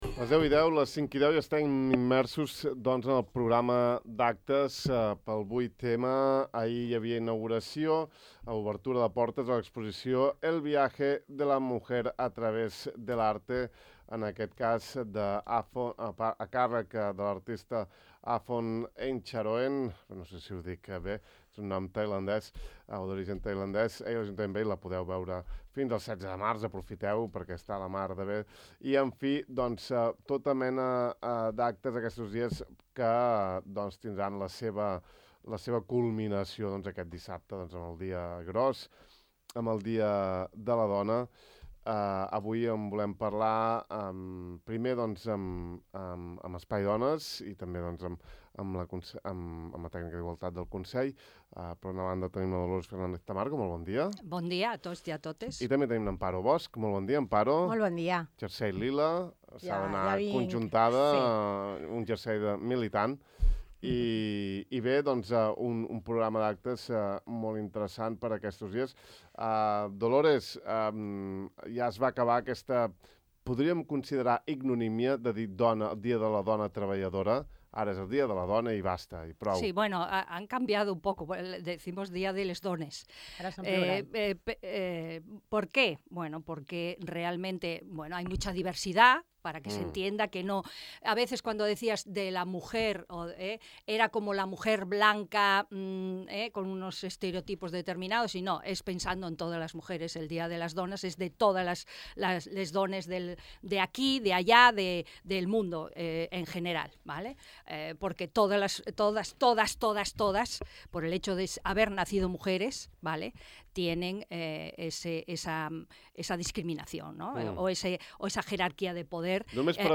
Podeu consultar tot el programa aquí, i escoltar l’entrevista sencera sota: